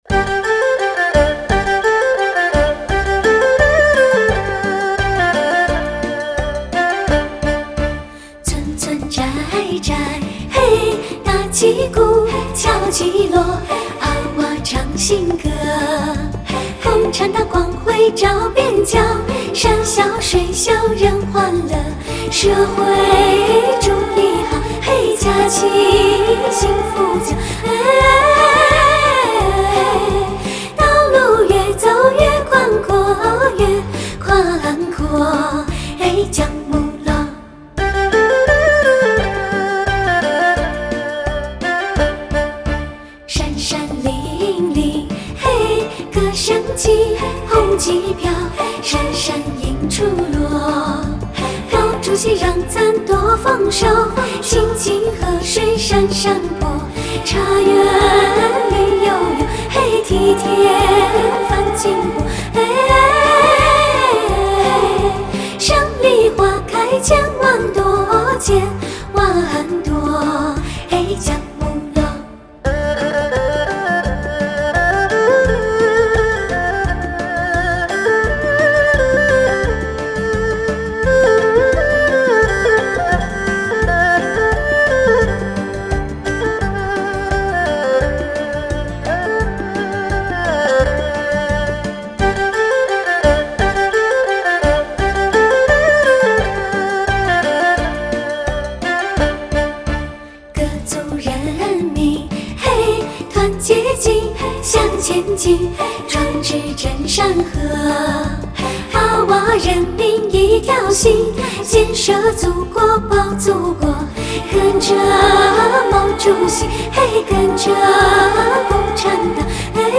优美红色经典